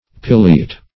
Pileate \Pi"le*ate\, Pileated \Pi"le*a`ted\, a. [L. pileatus,